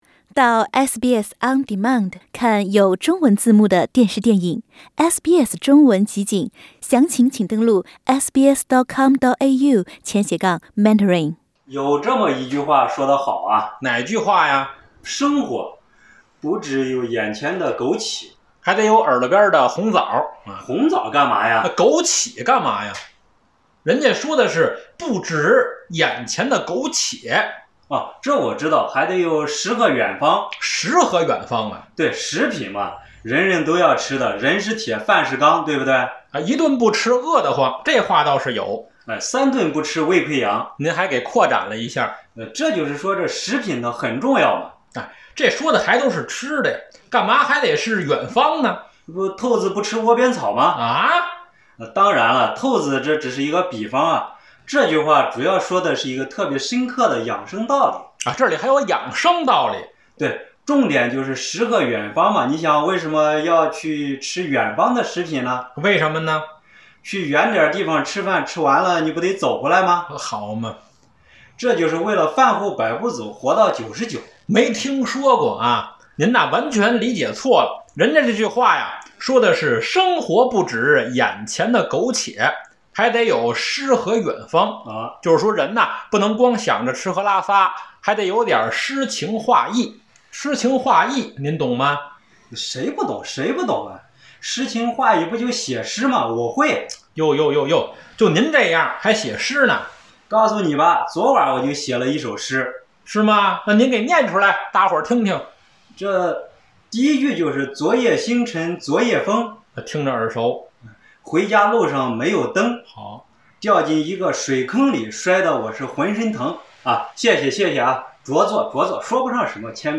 相声作品：诗和远方